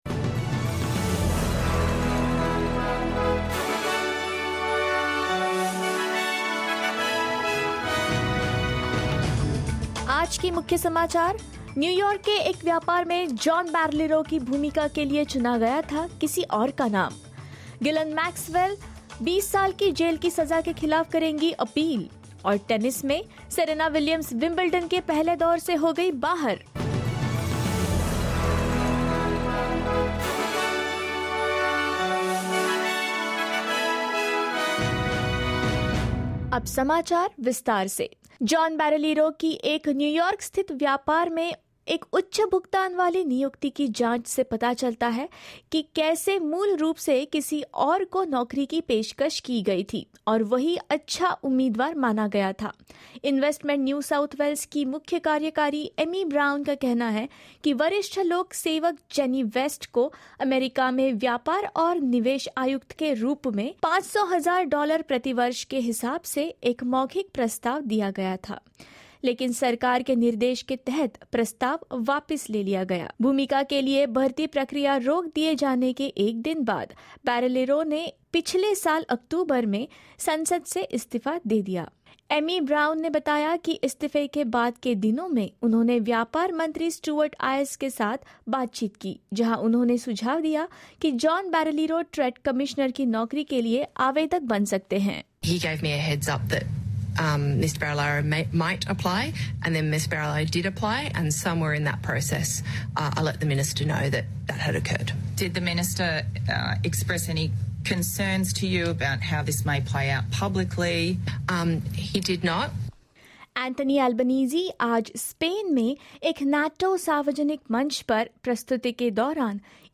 In this latest SBS Hindi bulletin: Lawyers for Ghislaine Maxwell say they plan to appeal her 20 year prison sentence; Investment NSW chief executive Amy Brown says she has no regrets about approving John Barilaro for the role; Tennis star Serena Williams knocked out of Wimbledon in the first round and more.